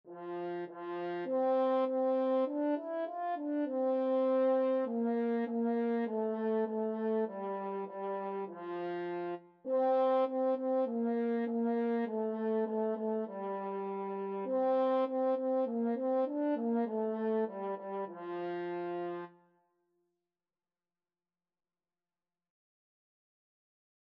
Traditional Trad. Baa Baa Black Sheep French Horn version
4/4 (View more 4/4 Music)
Moderato
F major (Sounding Pitch) C major (French Horn in F) (View more F major Music for French Horn )
French Horn  (View more Beginners French Horn Music)
Traditional (View more Traditional French Horn Music)